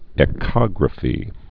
(ĕ-kŏgrə-fē)